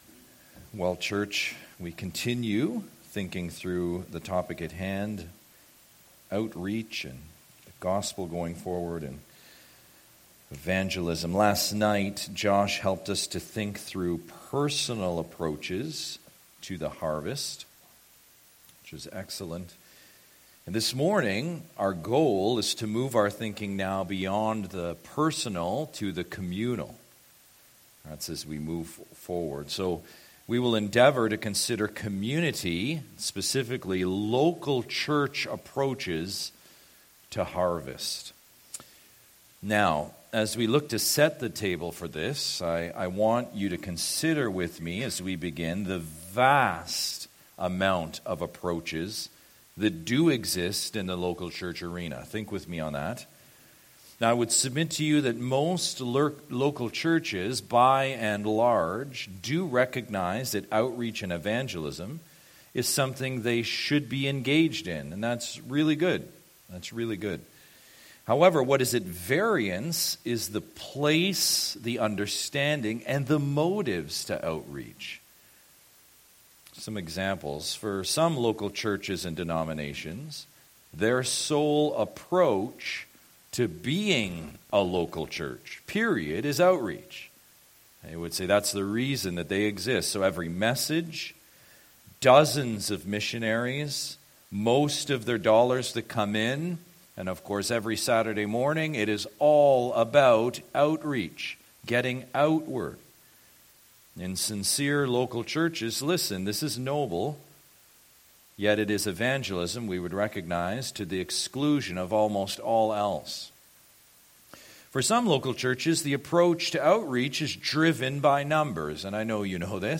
Fall Conference 2024